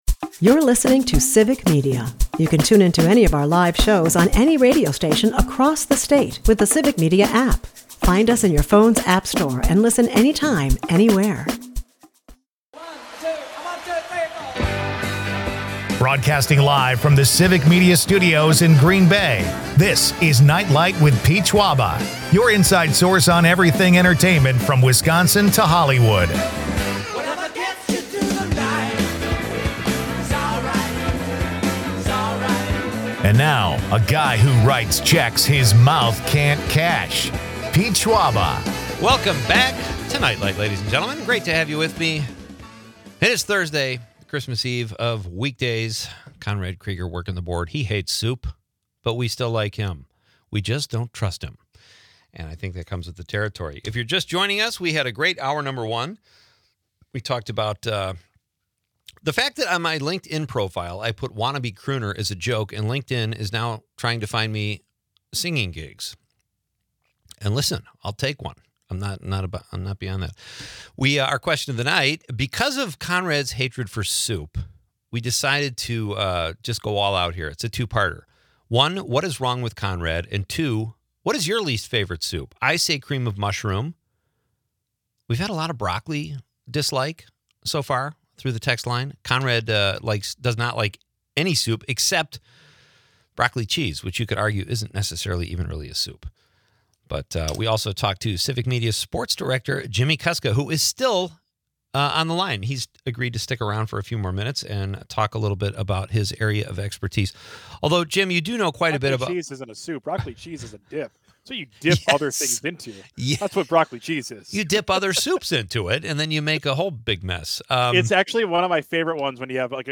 The episode wraps up with listener texts about peculiar soup preferences and a preview of an artist painting a couch potato live on air. Expect laughs, sports banter, and a dash of Wisconsin culture.